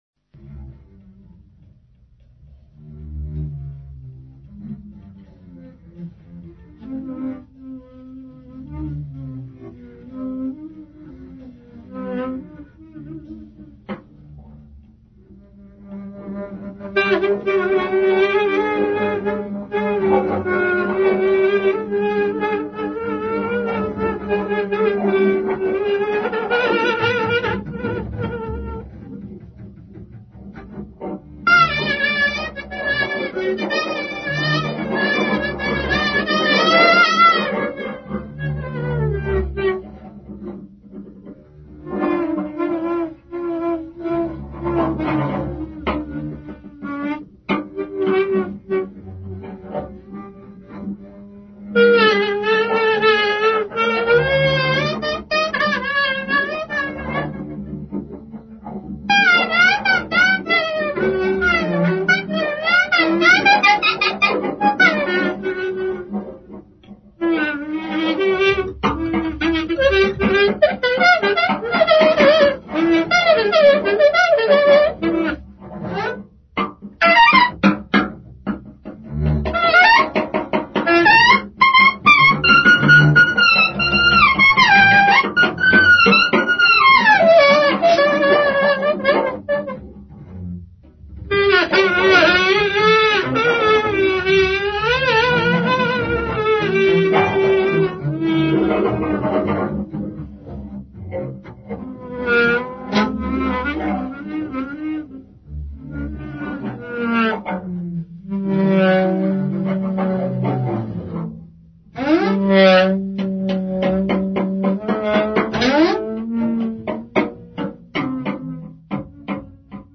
Recordings in Boston:
alto saxophone
violin
bass
drums